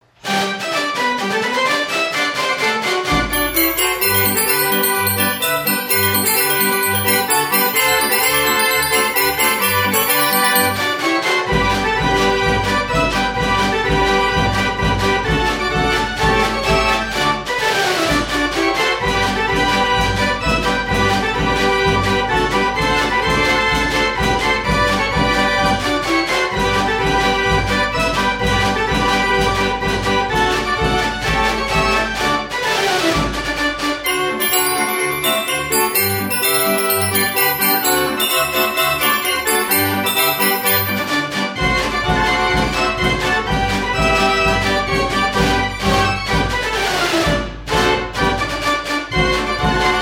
It has 54 keys and plays from paper rolls of music.
Traps: bass drum; snare drum and cymbal.